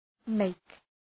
Προφορά
{meık}